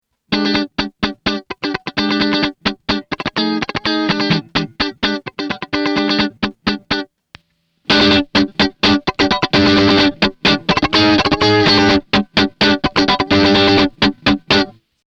Treble-Booster
In diesem Beispiel arbeitet der Range vor einem Fender VibroVerb und wird vom Volumenregler der Gitarre ausgesteuert.
Gleiches Set - Clean. Ein Funky-Riff setzt sich besser durch.
Range_Funky.mp3